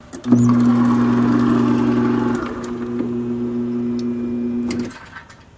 Electric Door, Grocery, Close